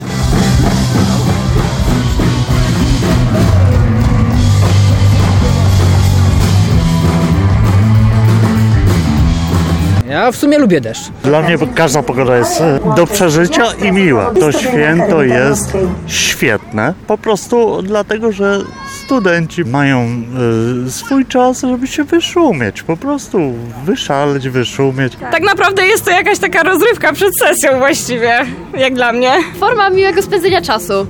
Studenci, którzy pojawili się na wydarzeniu przyznali, że deszcz nie przeszkodził im w dobrej zabawie.